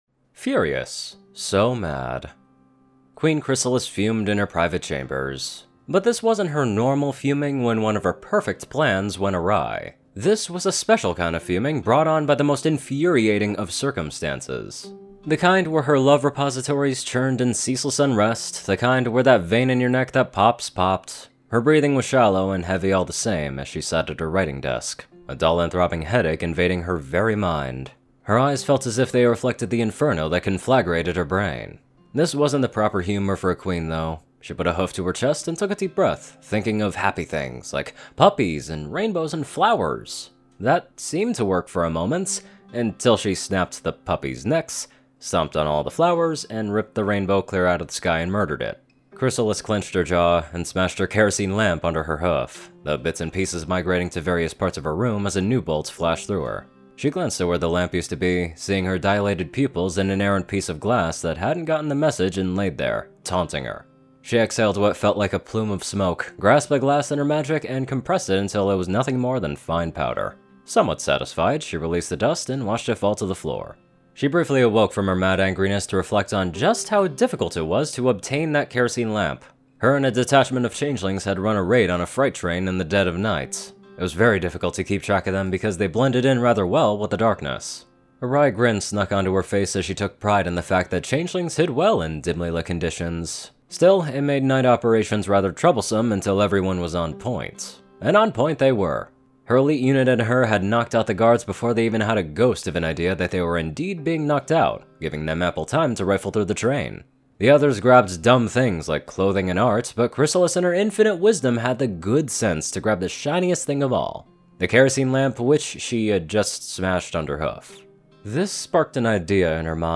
Early Access Reading To "Furious!"